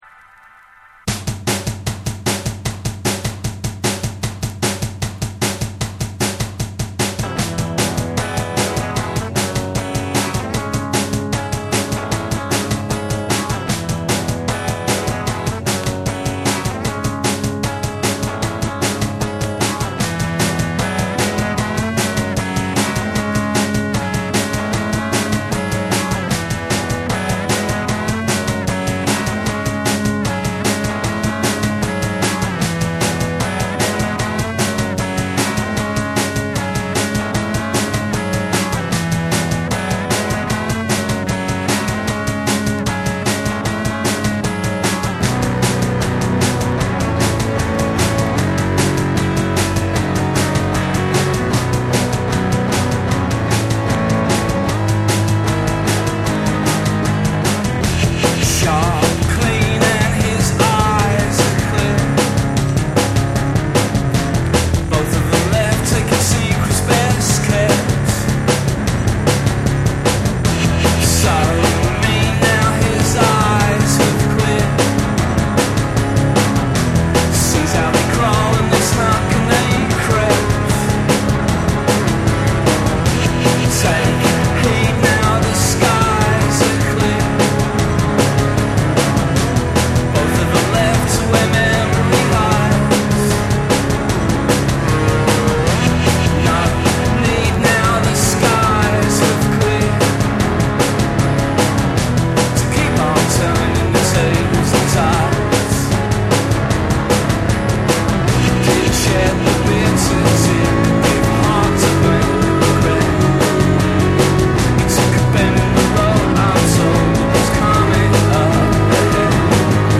BREAKBEATS / NEW WAVE & ROCK